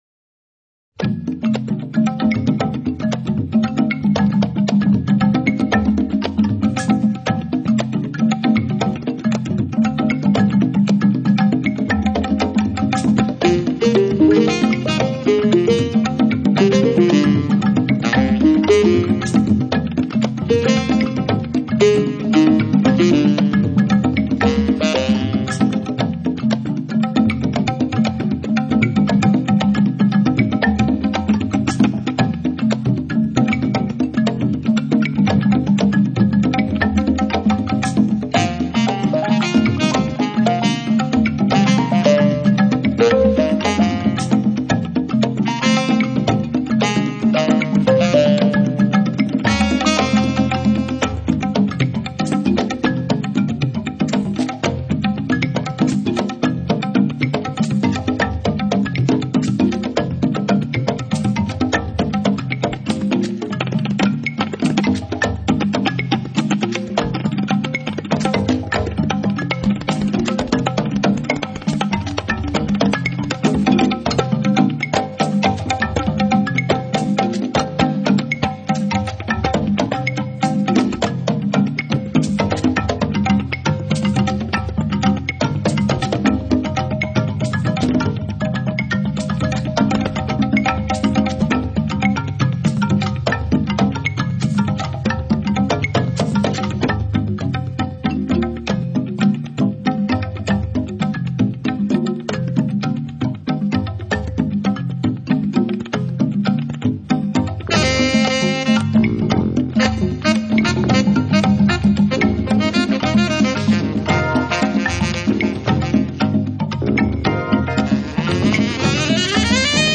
tabla
gato-drum
marimba